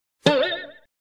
Cartoon Bounce Sound Effect